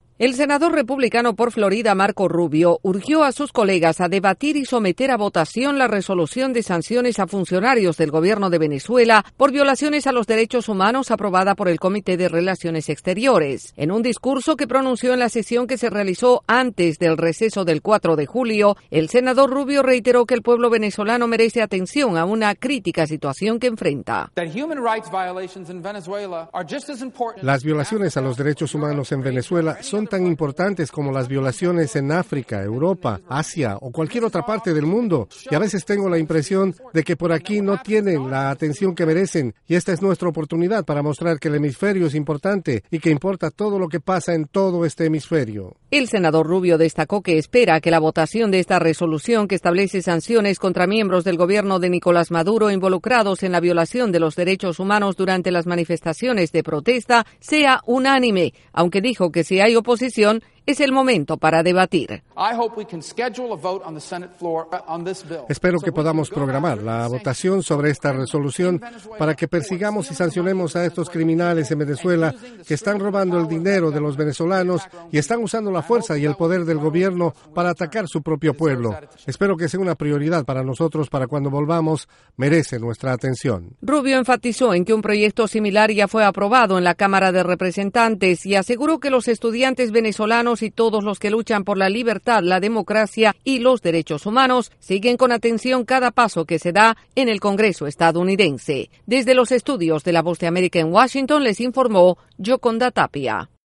El senador republicano, Marco Rubio, urgió a sus colegas a considerar y aprobar la resolución para ejercitar sanciones contra funcionarios gubernamentales venezolanos involucrados en violaciones a los derechos humanos. Desde la Voz de América en Washington DC informa